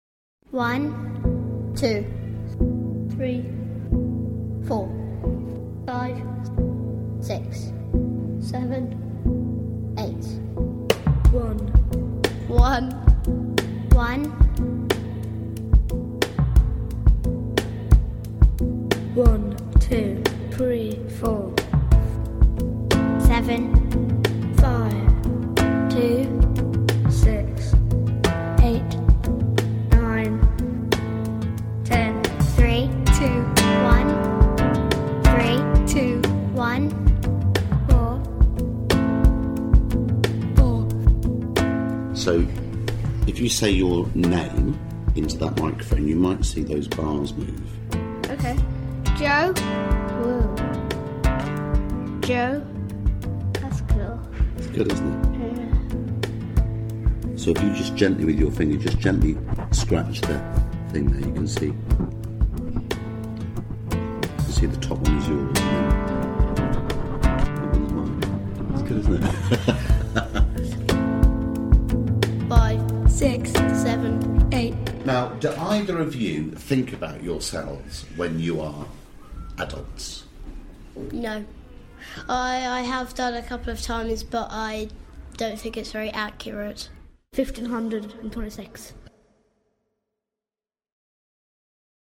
A little behind-the-scenes remix of some of the contributors to Monkey Bars to say thank you to everyone and to the cast as they take the show out onto the road again this year.